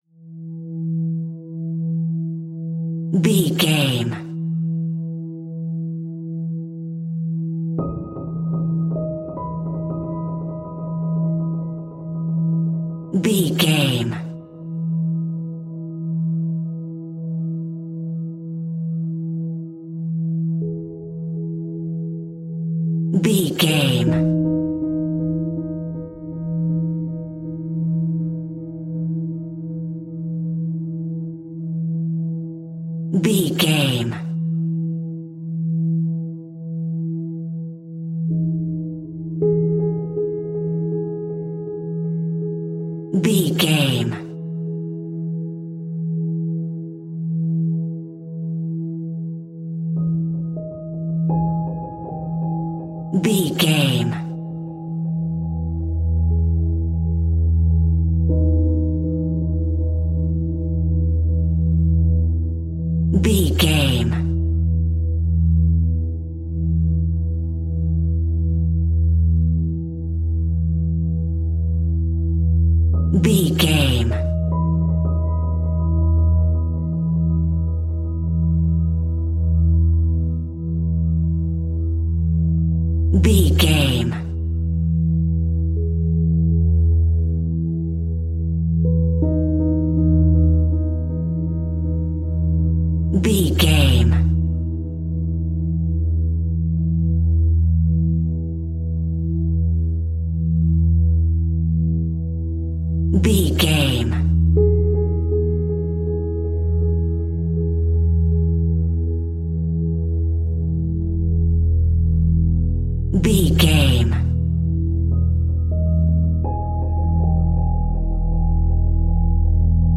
Atmospheric Scary Piano Drone.
Aeolian/Minor
Slow
ominous
dark
haunting
eerie
strings
synthesiser
horror music